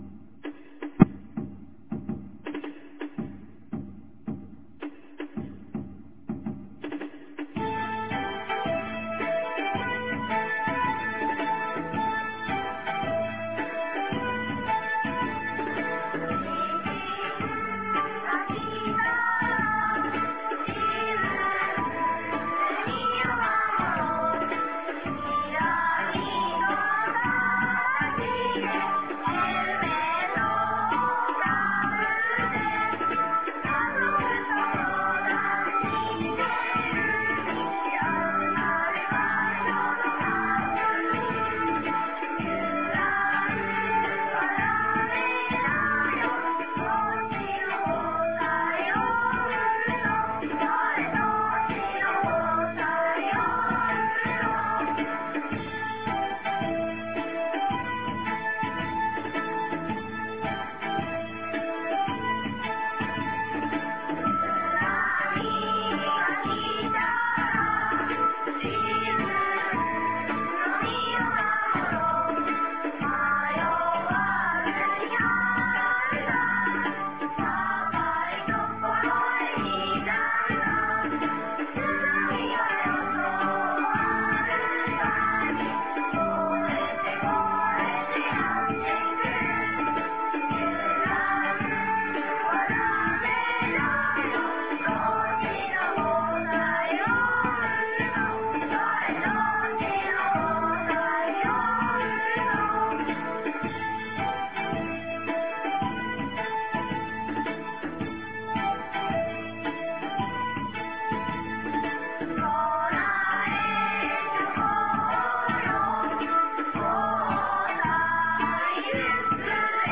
2023年03月11日 17時03分に、南国市より放送がありました。